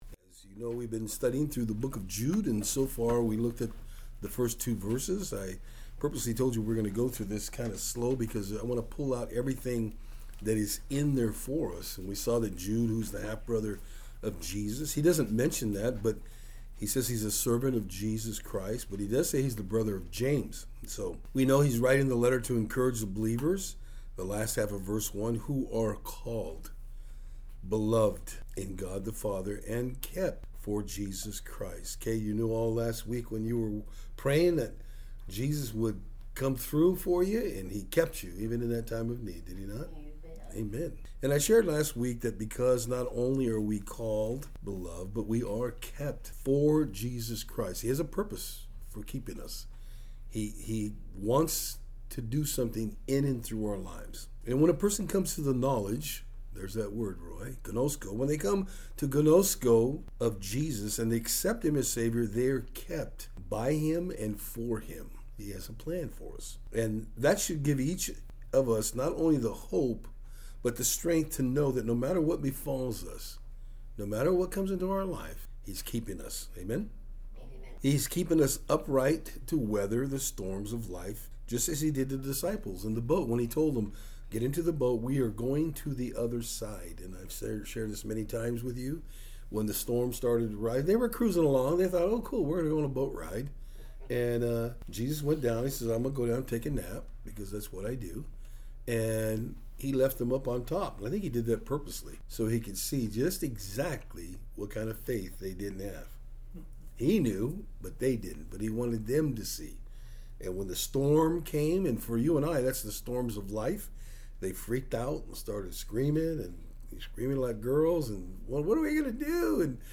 Jude 3-4 Service Type: Thursday Afternoon In our study of Jude today we will be looking at exactly what it is to “contend” for the faith.